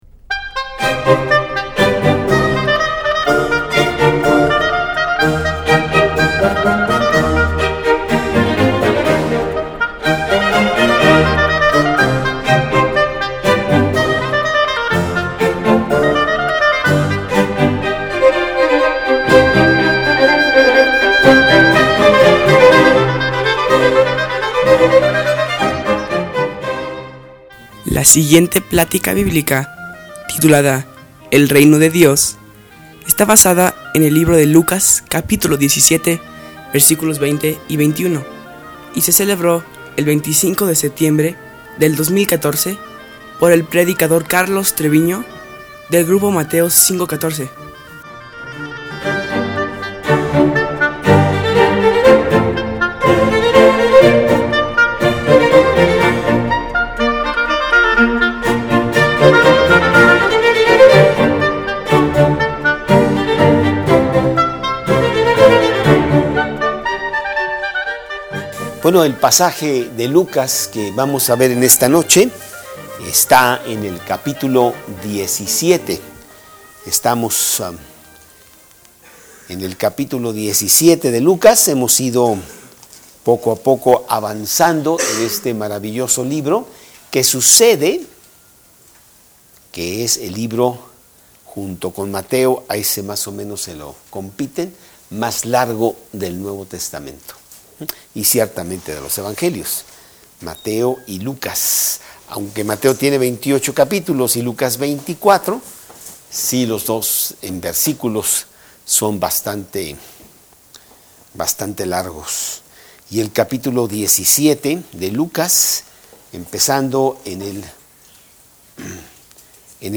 2014 El Reino De Dios Preacher